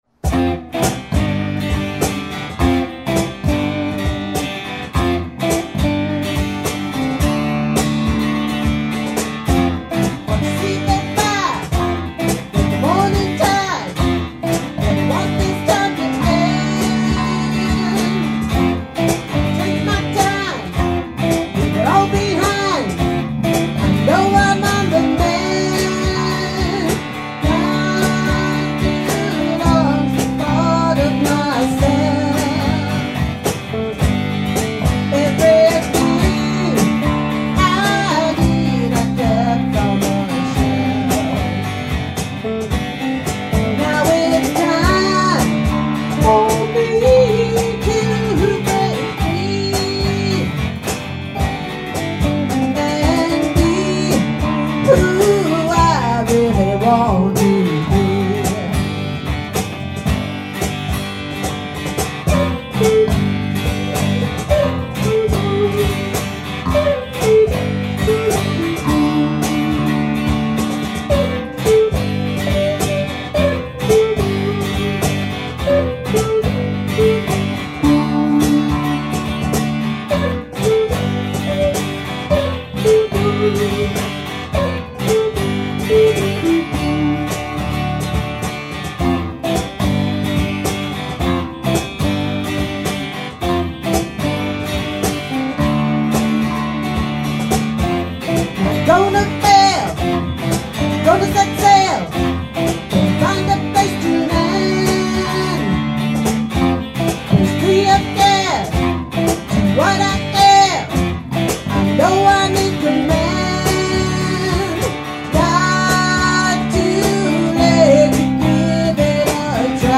Strat
Acoustic and vocals
Bass
Percussion